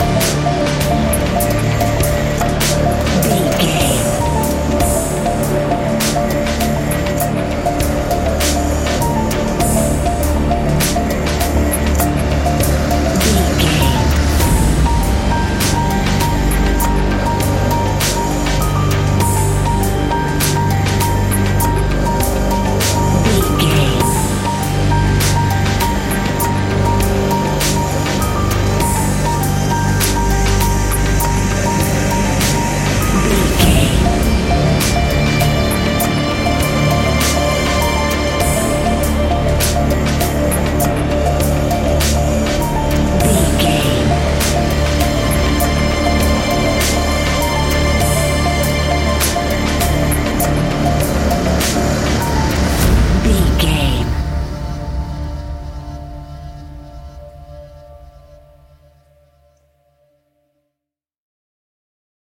Fast paced
In-crescendo
Ionian/Major
D♭
dark ambient
EBM
synths
Krautrock